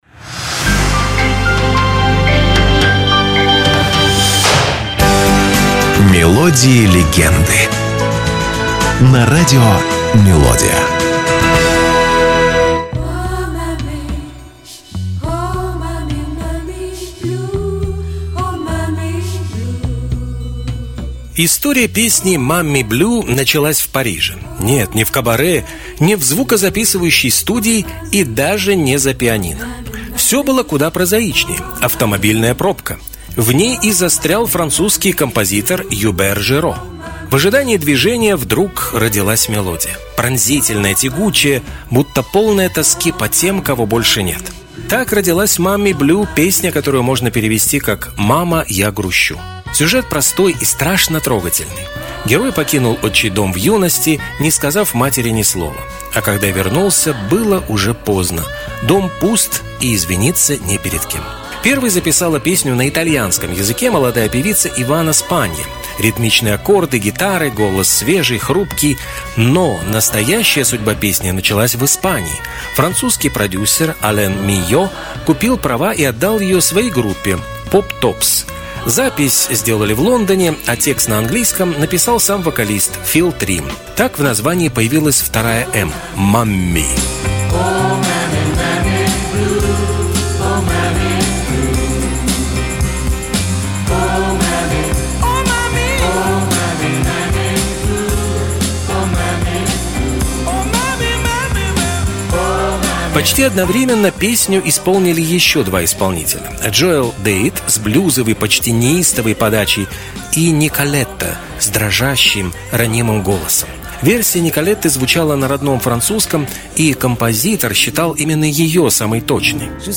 Это короткие музыкальные рассказы с душевным настроением, атмосферой ностальгии и лёгкой интригой. Вы услышите песни, которые звучали десятилетиями и, возможно, впервые узнаете, что стоит за этими знакомыми нотами.